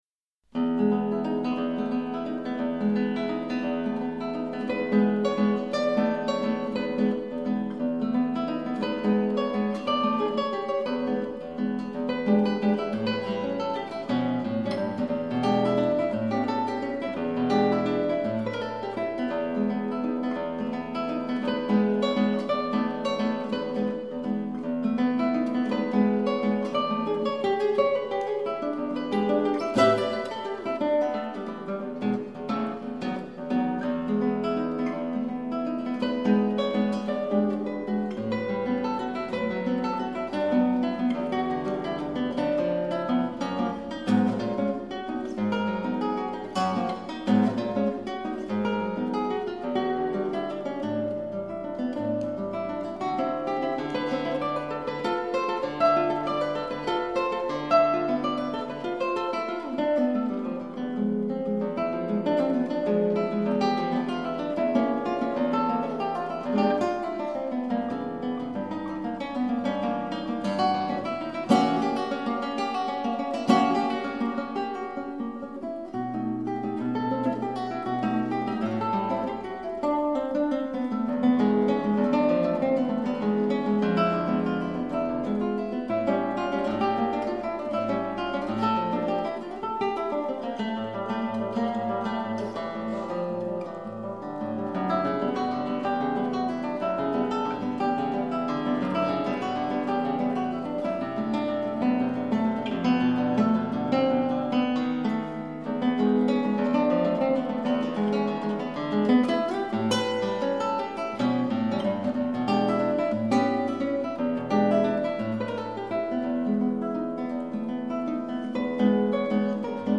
gitara